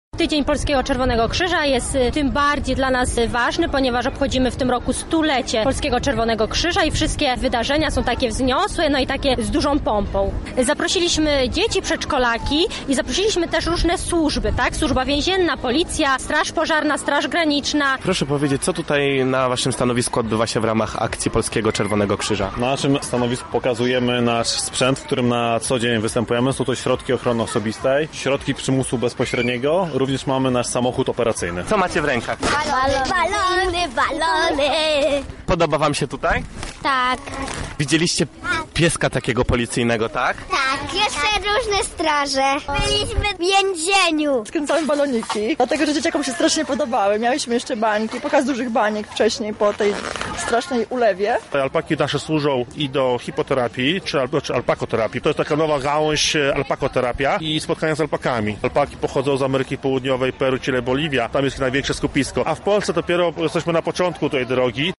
Wydarzenie odbywało się na Błoniach pod Zamkiem, a honorowym patronatem objęli Wojewoda Lubelski oraz Marszałek Województwa Lubelskiego.